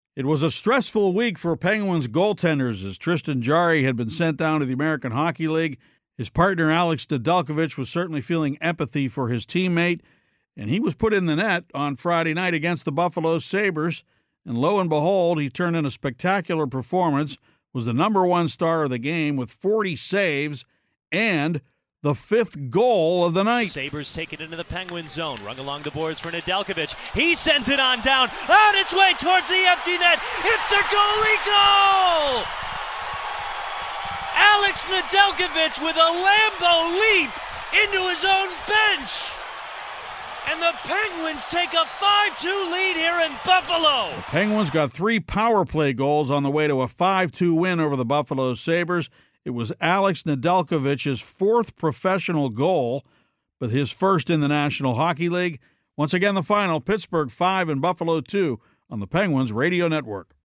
1-18-25-pens-recap.mp3